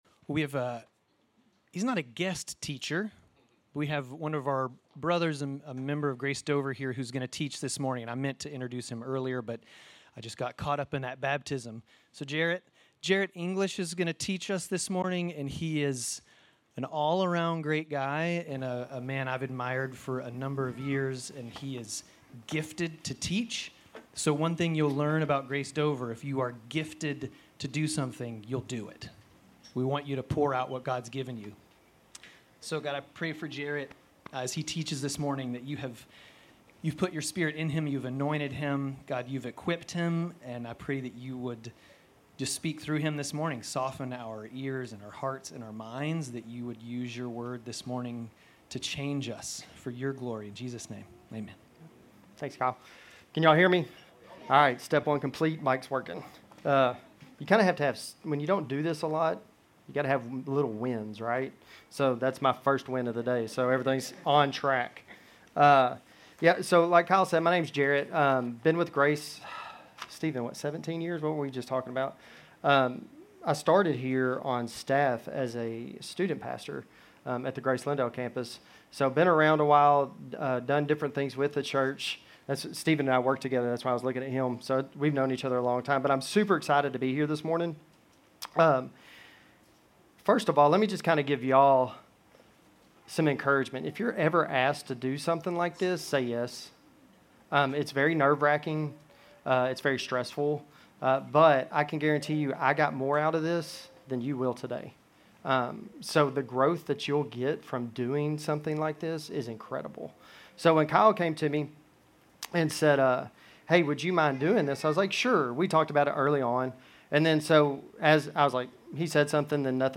Grace Community Church Dover Campus Sermons Gen 32:22-32 - Jacob wrestling with God Nov 17 2024 | 00:32:40 1x 00:00 / 00:32:40 Subscribe Share RSS Feed Share Link Embed